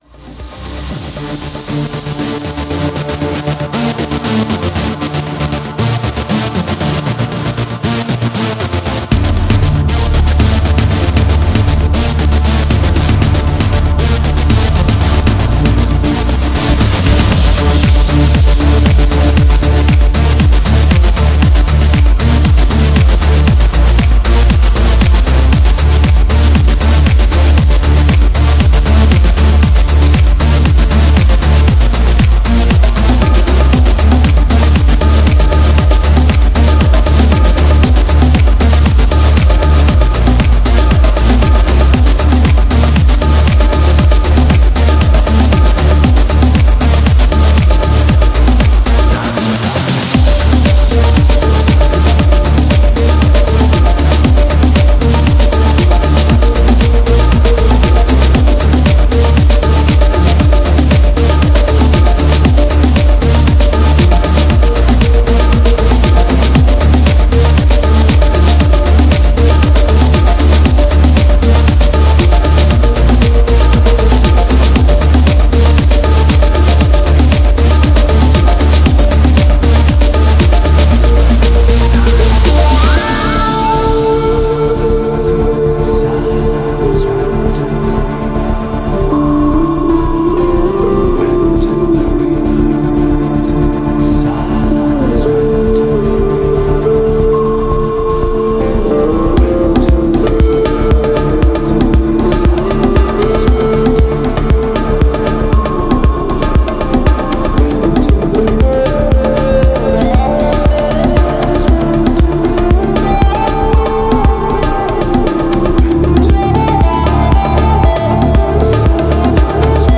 French producer and Dj of Belarusian origin.
Genre: Deep House